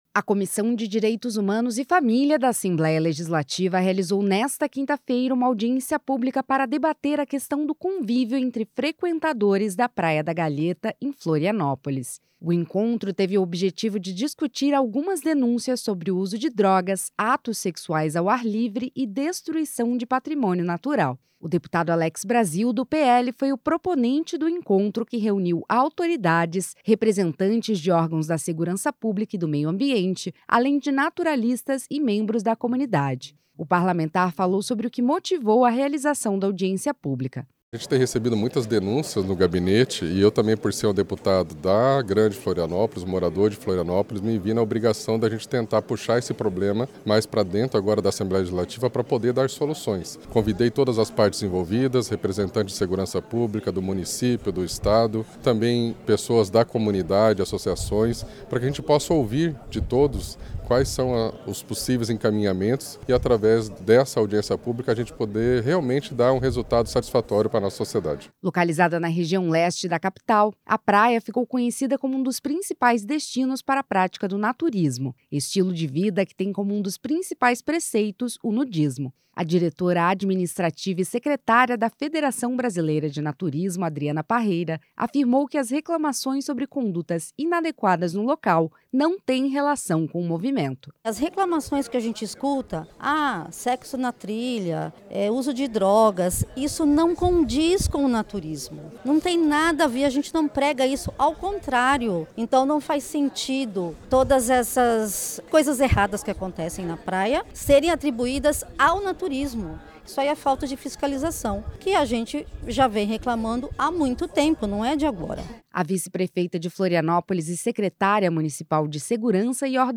Entrevistas com:
- deputado Alex Brasil (PL), presidente da Comissão de Direitos Humanos e Família da Alesc e proponente da audiência pública;
- Maryanne Mattos, vice-prefeita de Florianópolis e secretária Municipal de Segurança e Ordem Pública.